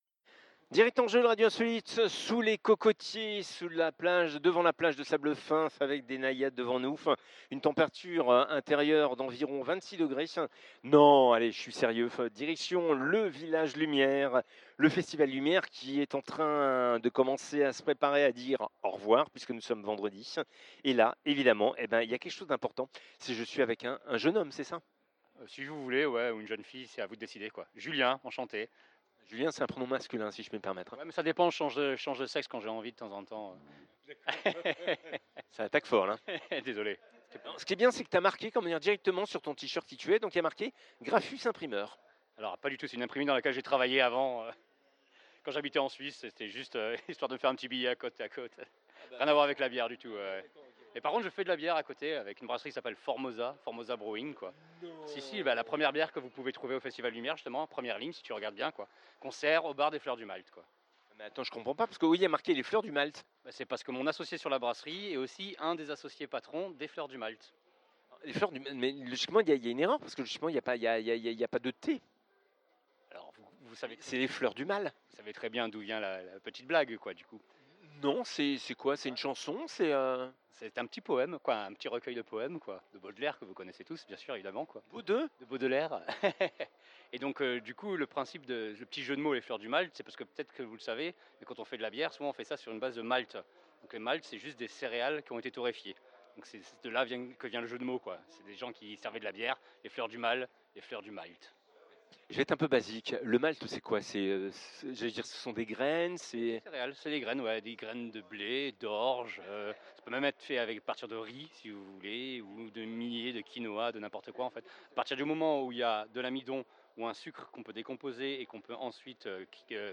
bar à bières Les Fleurs du Malt, en direct du Village Lumière 21